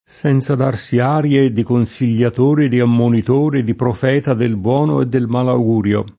malaugurio [malaug2rLo] s. m.; pl. (raro) ‑ri (raro, alla lat., ‑rii) — meno com. mal augurio [id.]: senza darsi arie di consigliatore, di ammonitore, di profeta del buono e del mal augurio [